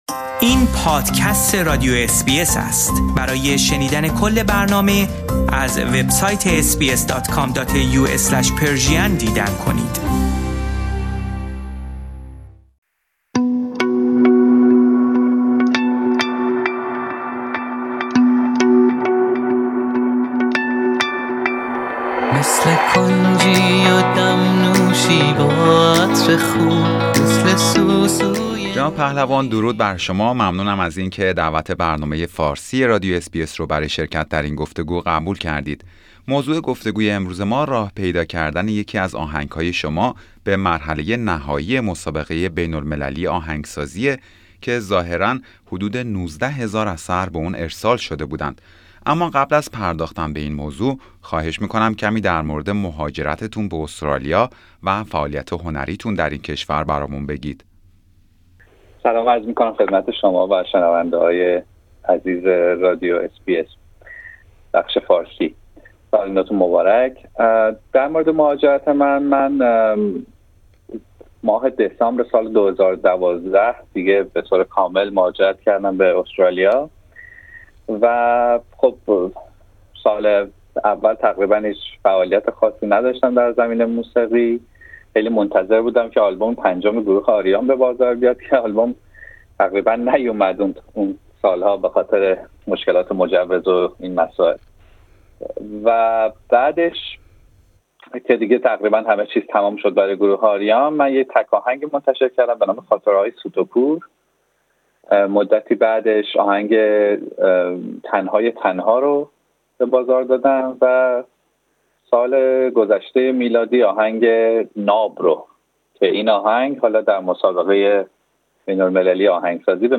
گفتگویی